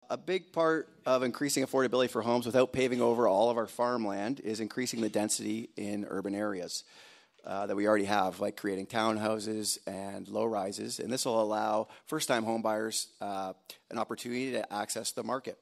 The Haldimand-Norfolk candidates met at the Royal Canadian Legion in Simcoe on Thursday night.